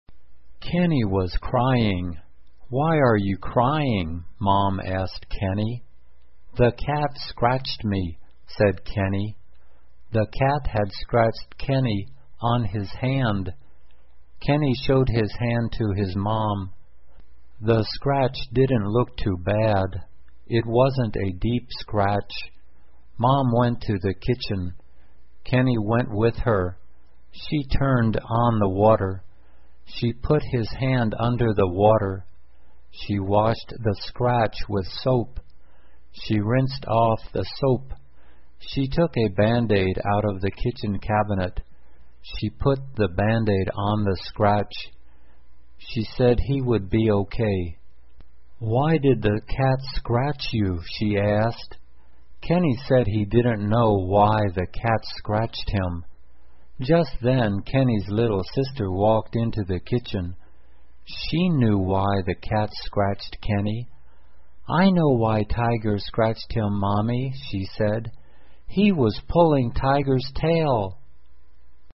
慢速英语短文听力 猫抓痕 听力文件下载—在线英语听力室